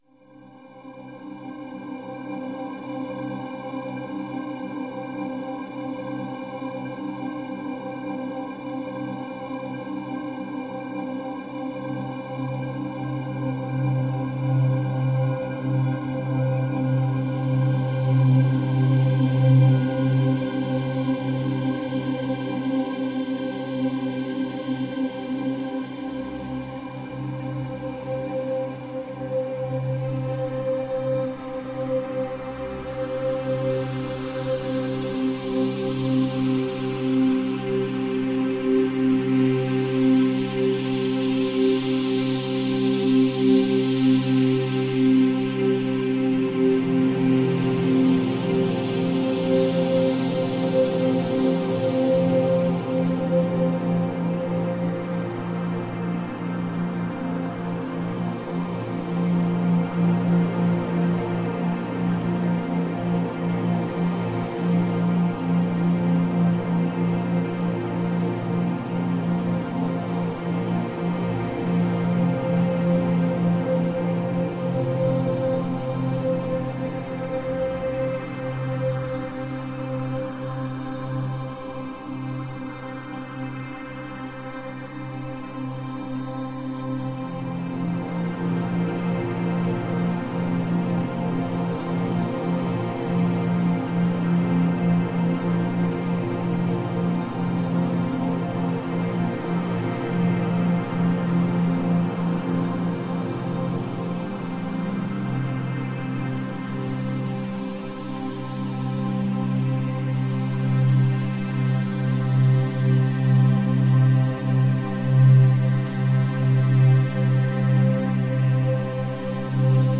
Ambient electronic and neoclassical soundscapes.
Tagged as: New Age, Ambient, Instrumental, Space Music